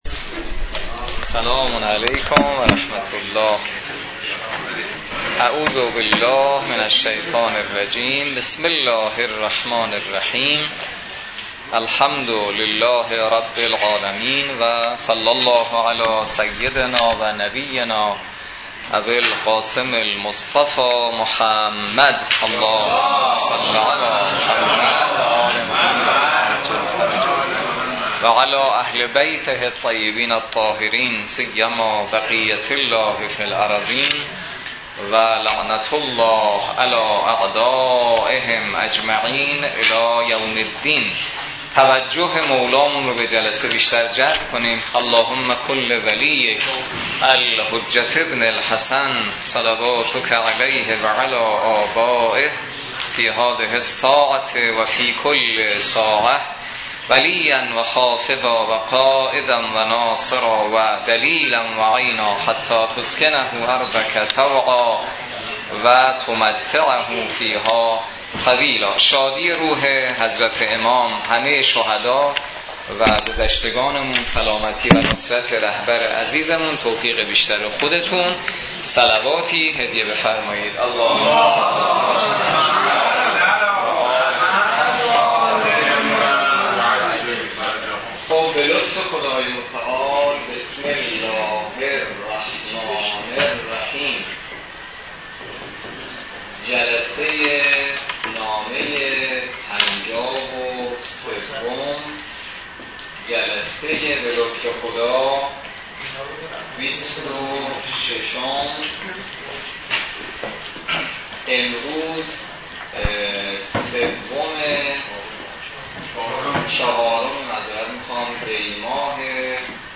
برگزاری بیست و ششمین جلسه مباحثه نامه ۵۳ نهج البلاغه توسط نماینده محترم ولی فقیه و در دانشگاه کاشان
بیست و ششمین جلسه مباحثه نامه ۵۳ نهج البلاغه توسط حجت‌الاسلام والمسلمین حسینی نماینده محترم ولی فقیه و امام جمعه کاشان در دانشگاه کاشان برگزار...